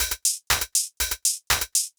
120_HH_1.wav